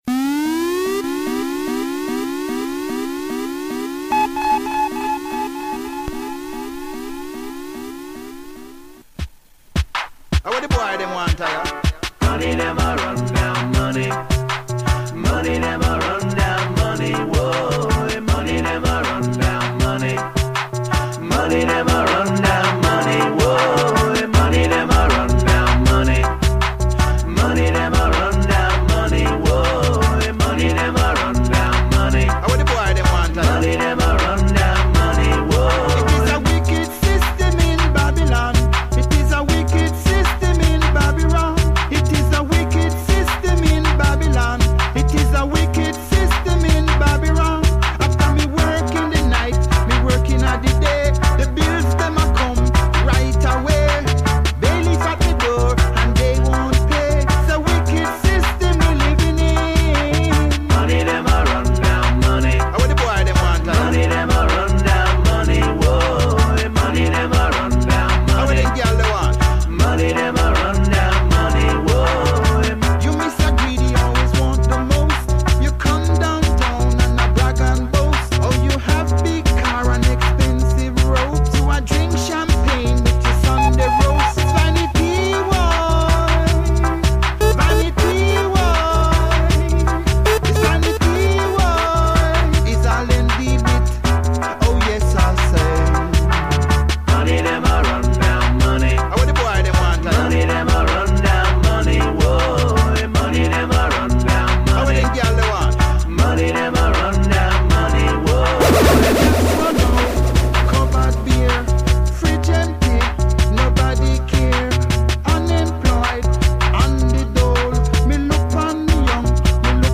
Money selection, hope this mix isn't too depressing!